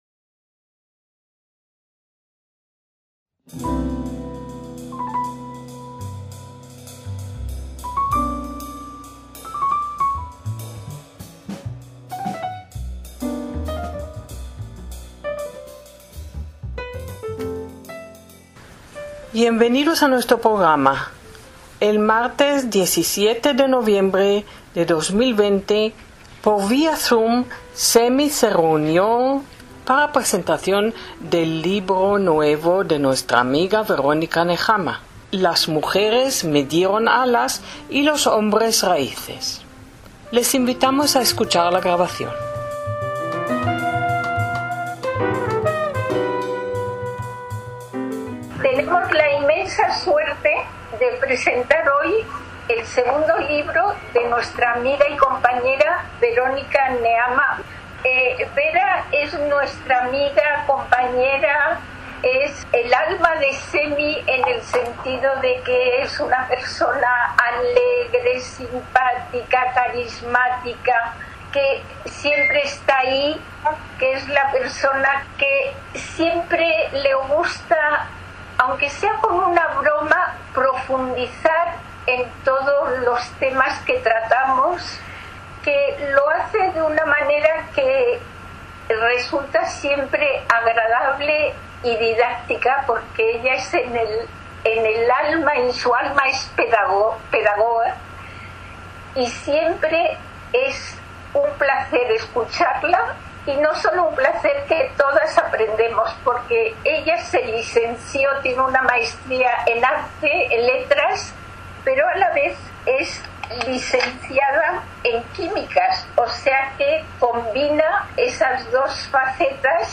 ACTOS "EN DIRECTO" - Nacidas en Filipinas, Egipto, España y Bolivia, cuatro mujeres entrelazan sus vidas, tejiendo un tapiz común cuyos arabescos reflejan una evolución que se ciñe al ritmo de los vertiginosos cambios sociales.